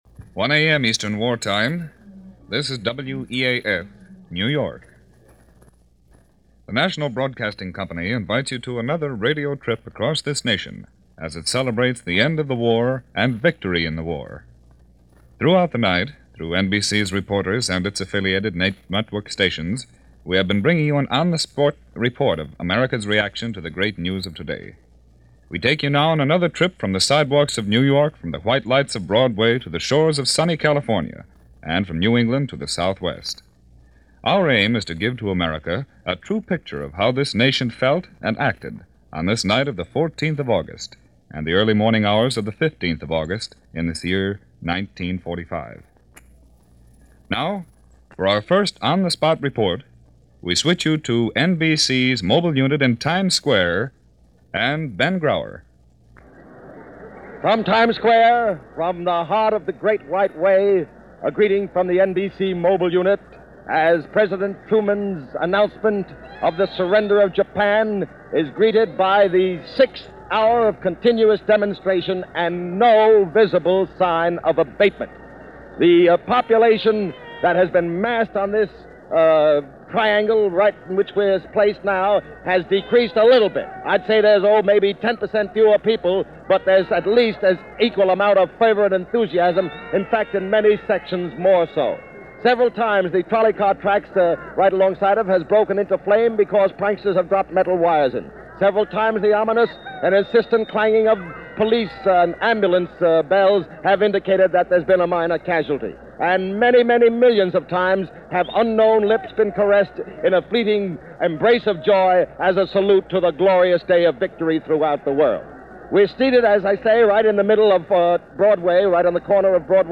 – August 15, 1945 – VJ Day celebrations continue – Reports around the Country – WEAF, New York – Gordon Skene Sound Collection –
All regular programs via Radio were pre-empted for reports, filed by hoarse, hungover and frazzled journalists and announcers as even the networks gave over to the continuous. ongoing celebration.